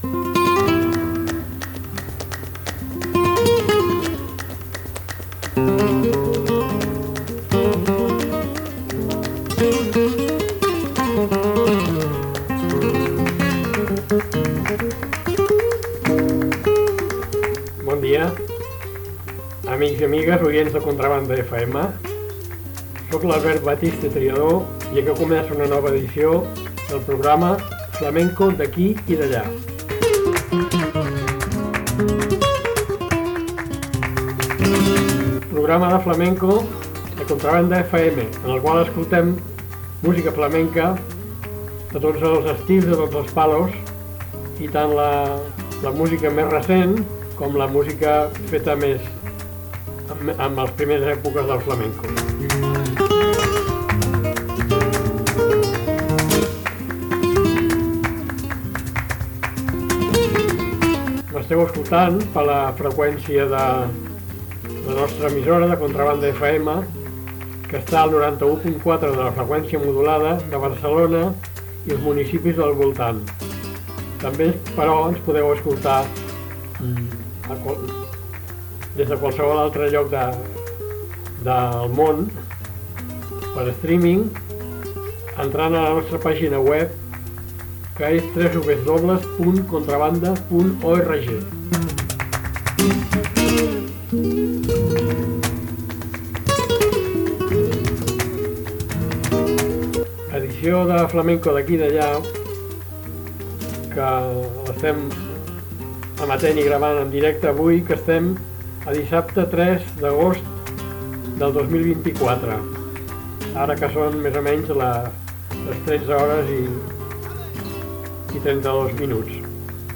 El fandango onubense, fill del fandango de Levante i els cantes a compàs de la Baixa Andalusia. Rocío Márquez cantaora onubense. Del fandango a la música urbana.